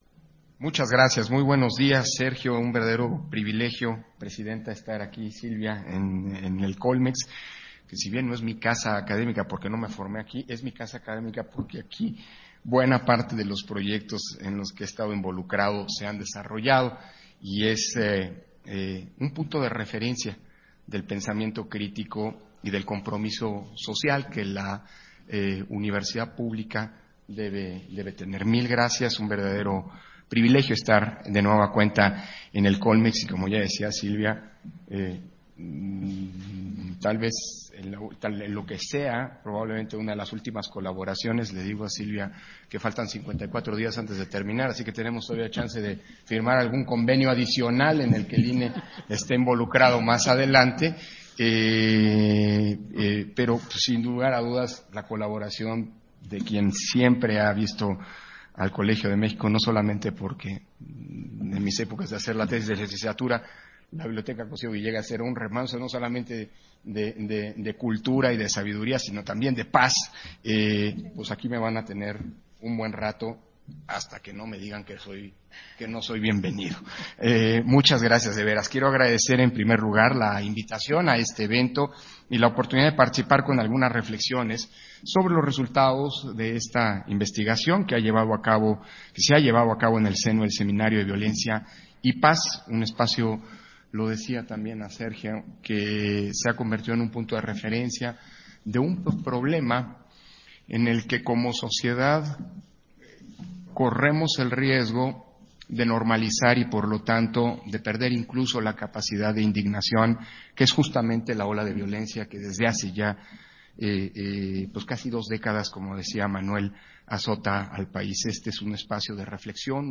Intervenciones de Lorenzo Córdova, en la presentación de las recomendaciones para la seguridad de los candidatos y candidatas a los cargos de elección popular en las elecciones de Coahuila y Edomex 2023